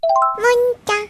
장르 알림 음